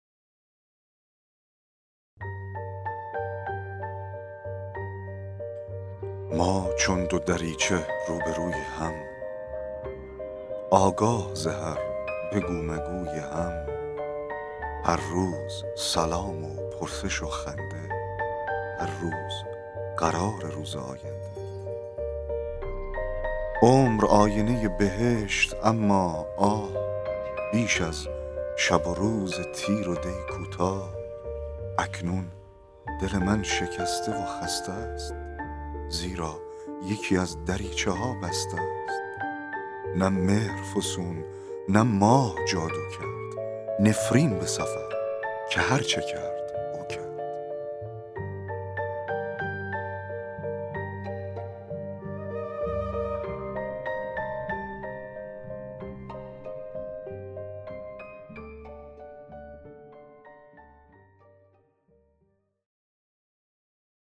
دکلمه شعر دریچه ها
دکلمه-شعر-دریچه-ها.mp3